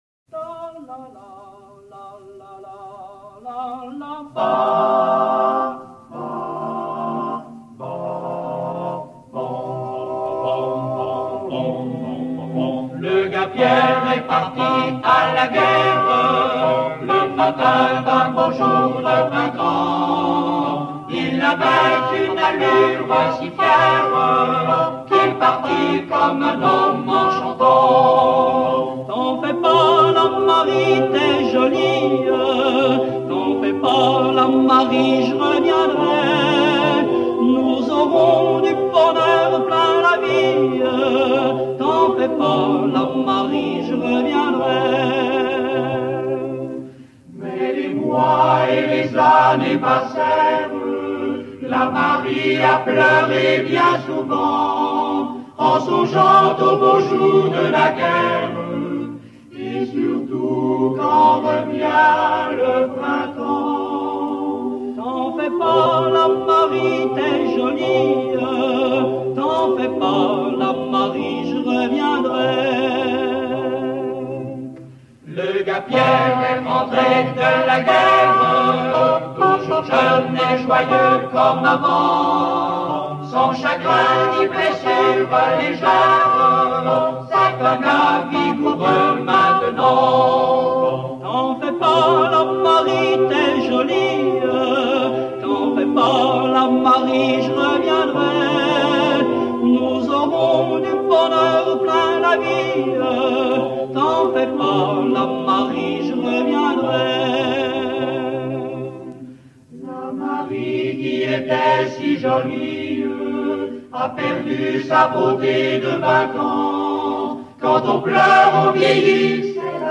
Chant de marche de la 13e DBLE · Autre titre